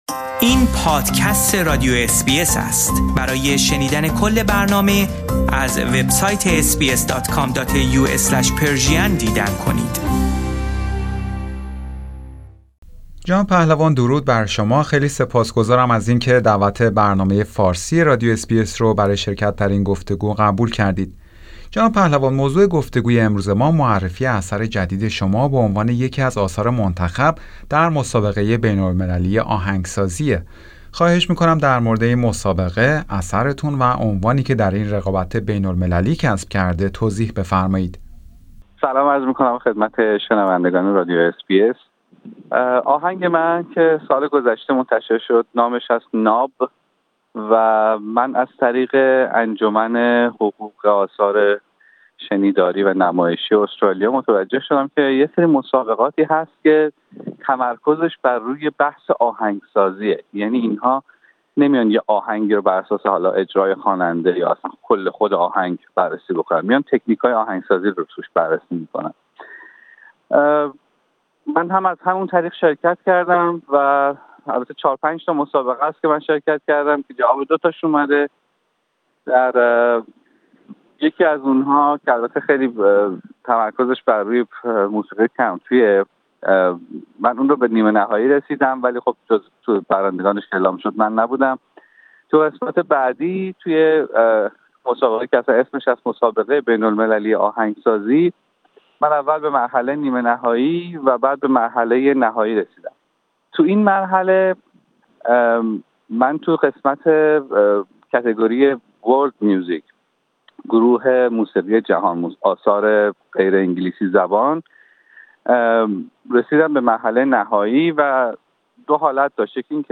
آقای پهلوان در گفتگو با برنامه فارسی رادیو اس بی اس، جزییاتی را در مورد عنوانی که آهنگش در این مسابقه بین المللی کسب کرده است و برخی از برنامه هایی که برای آینده دارد ارائه کرده است.